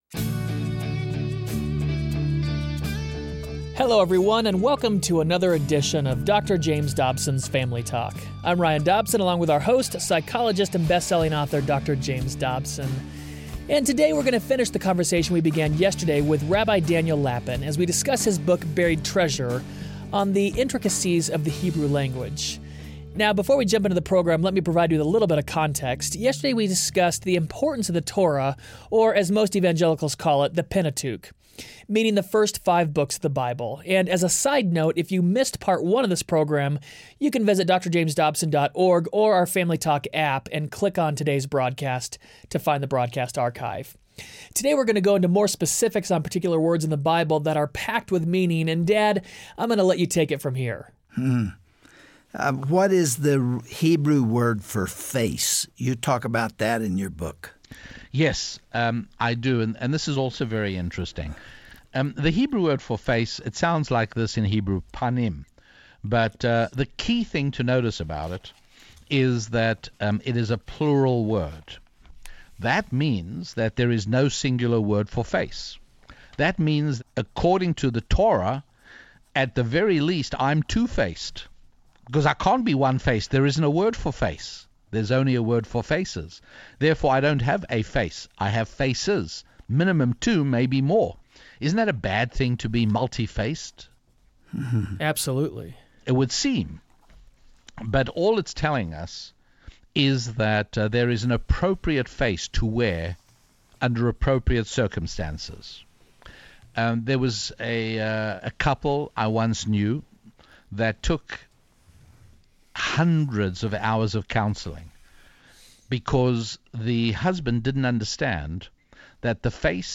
Sometimes English translations of the Bible miss amazing truths hidden in the original Hebrew and Greek. On the next edition of Family Talk, Dr. James Dobson will interview Rabbi Daniel Lapin about Buried Treasure in the Biblical text.